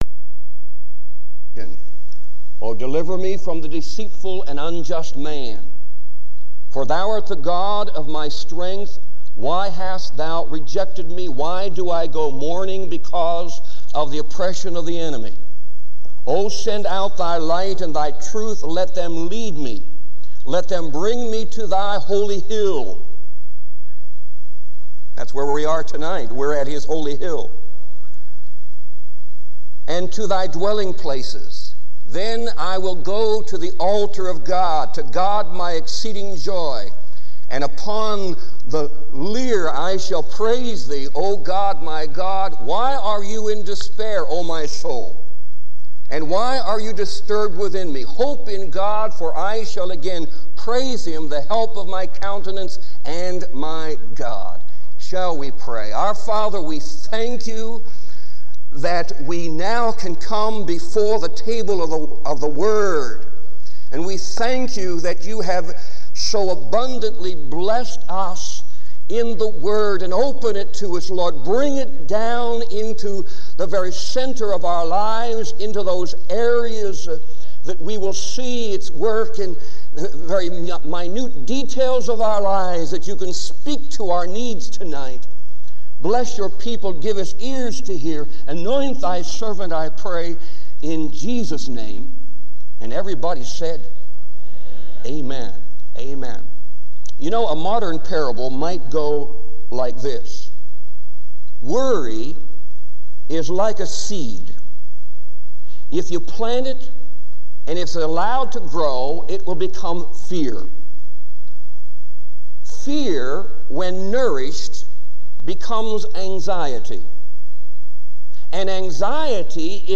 This sermon offers both encouragement and practical guidance for overcoming fear through trust in God.